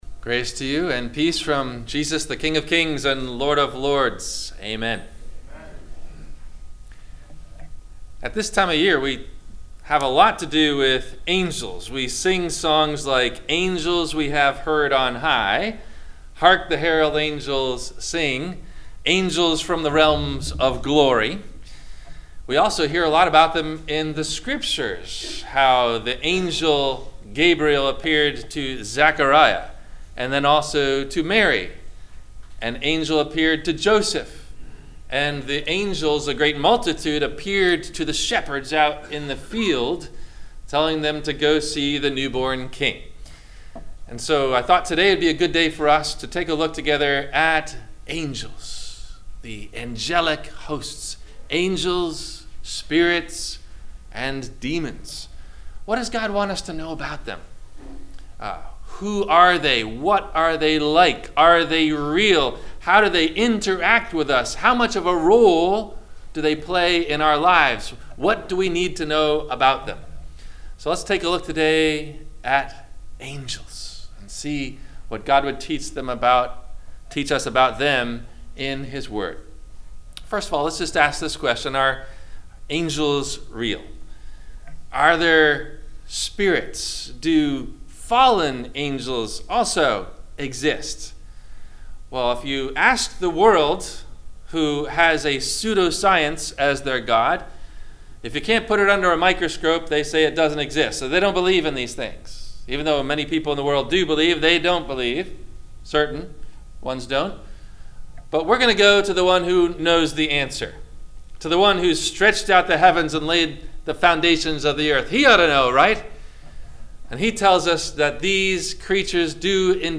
No Questions asked before the Sermon message: